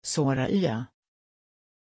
Pronunciation of Sorayah
pronunciation-sorayah-sv.mp3